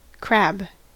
crab-us.mp3